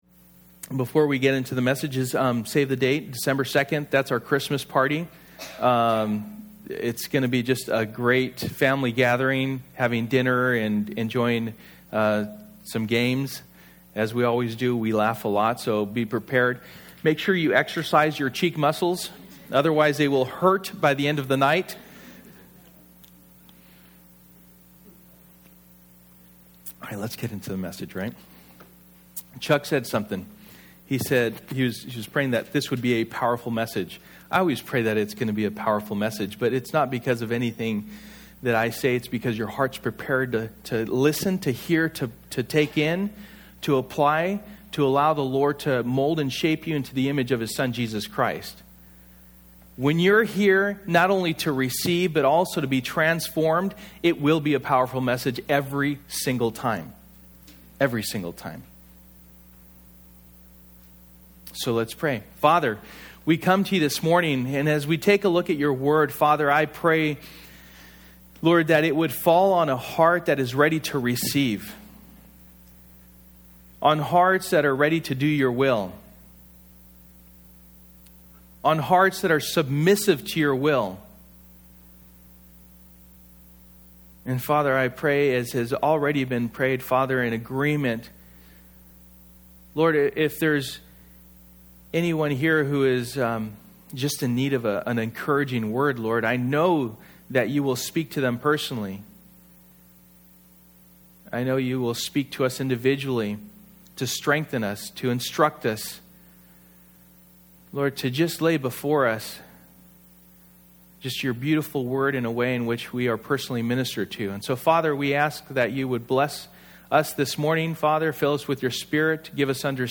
Stand Fast Passage: 1 Peter 2:11-25 Service: Sunday Morning